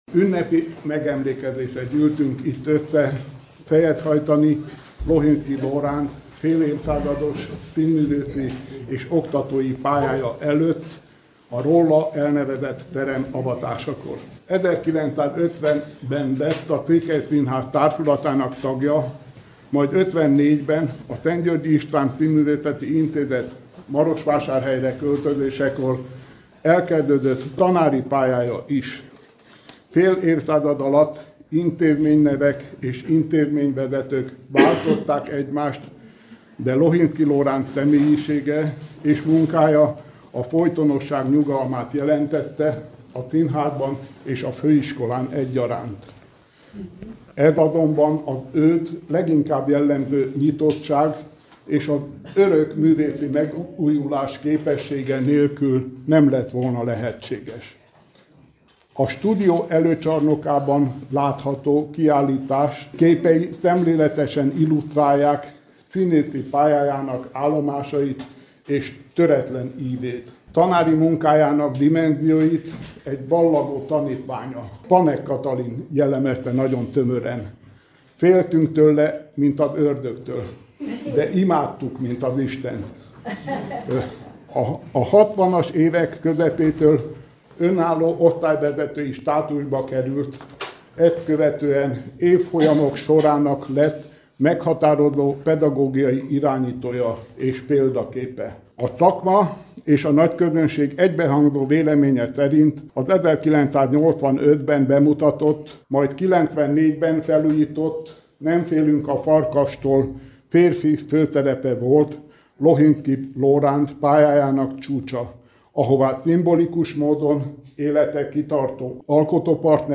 Az ünnepélyes terem- és plakett-avatón